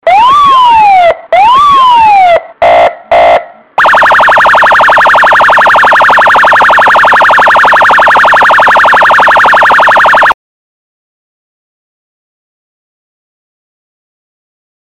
Sirene de Polícia
Sirene da polícia Rota Áudio Hello Moto Estourado
Categoria: Sons de sinos e apitos
Este som icônico e impactante vai chamar atenção onde quer que você esteja.
sirene-de-policia-pt-www_tiengdong_com.mp3